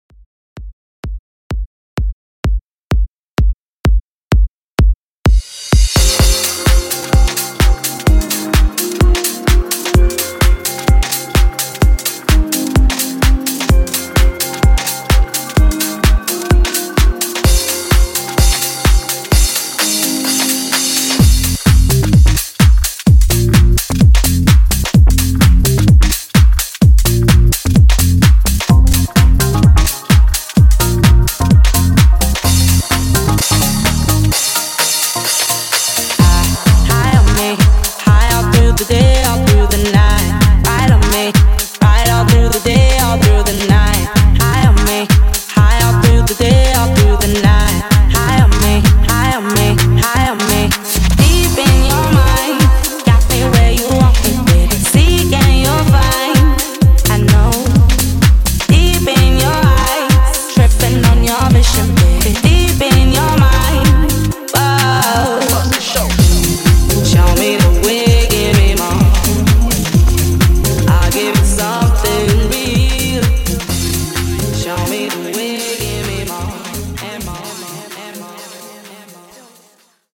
Intro Club Edit)Date Added